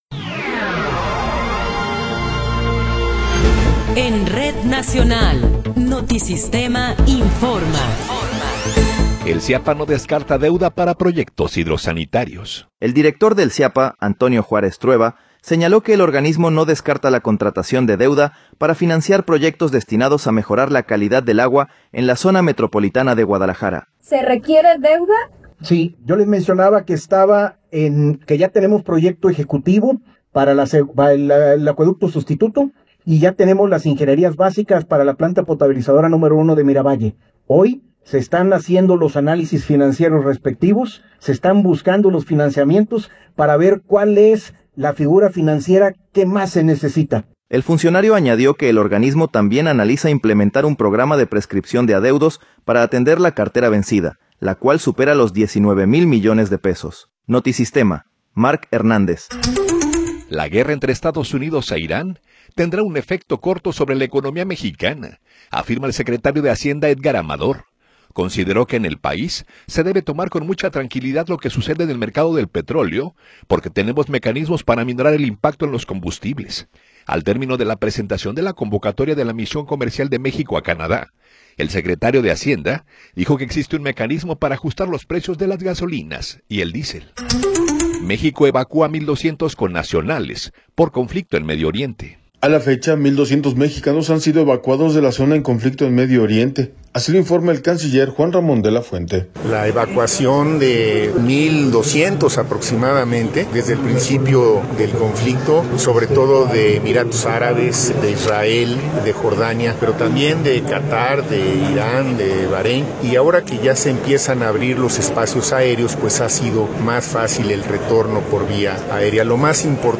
Noticiero 19 hrs. – 12 de Marzo de 2026